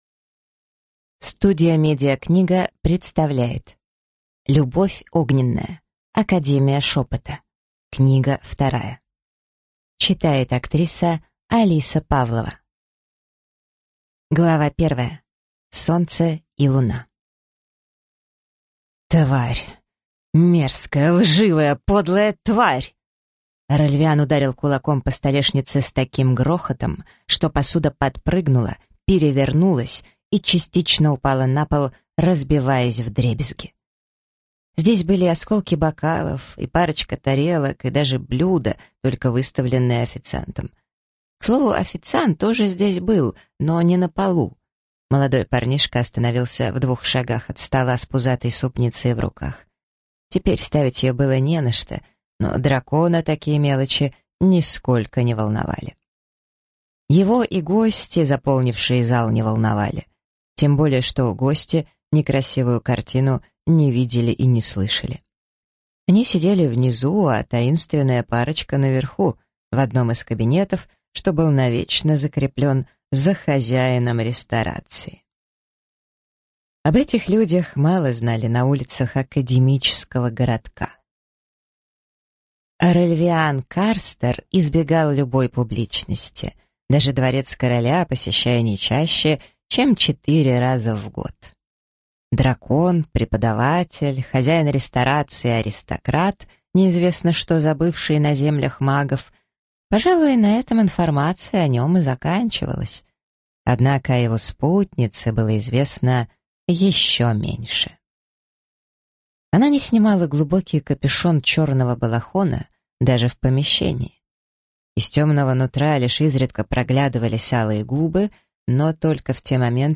Аудиокнига Академия Шепота. Книга 2 | Библиотека аудиокниг